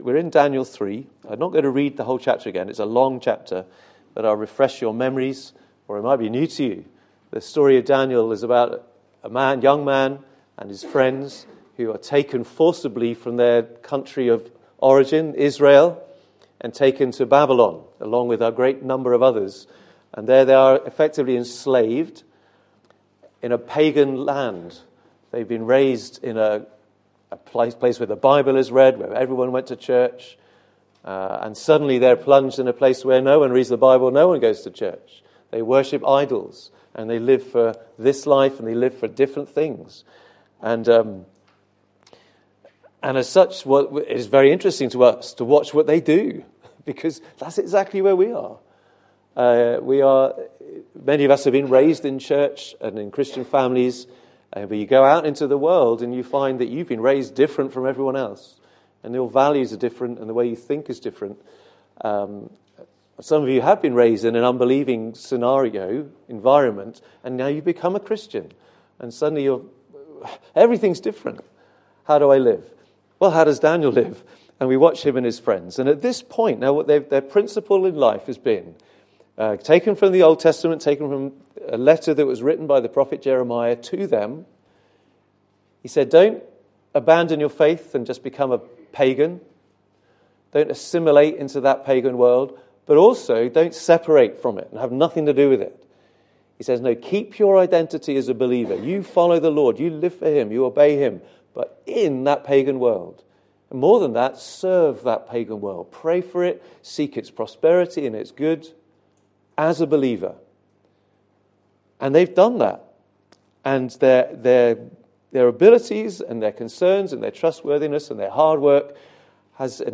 Book of Daniel Passage: Daniel 3:1-30, 1 Peter 4:12-19 Service Type: Sunday Morning « Rocky Ground Jephthah